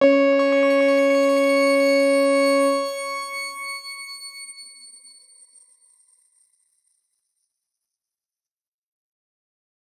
X_Grain-C#4-ff.wav